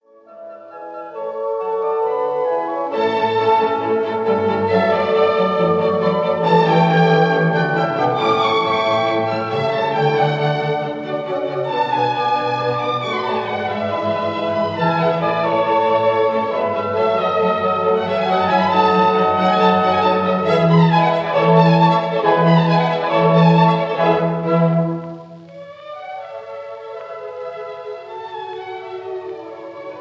The recording was made in the Kingsway Hall, London